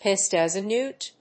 (as) píssed as a néwt＝píssed òut of one's héad [mínd]
発音